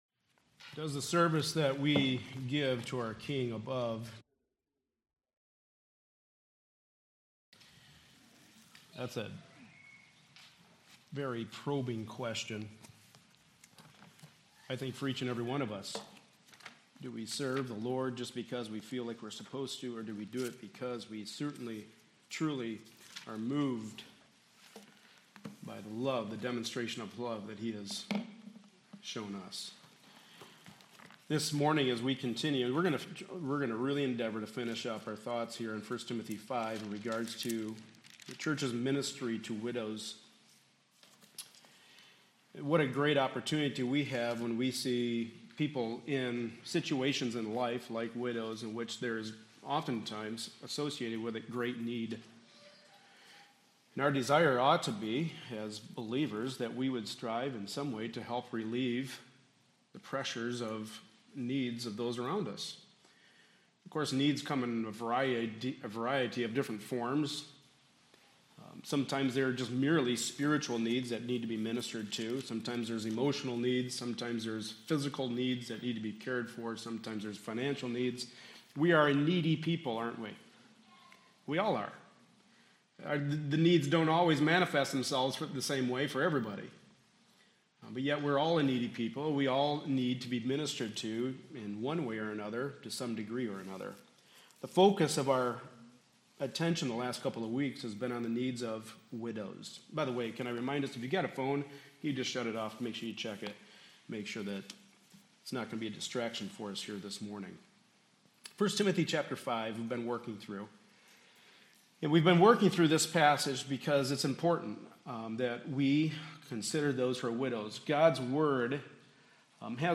Passage: 1 Timothy 5:3-16 Service Type: Sunday Morning Service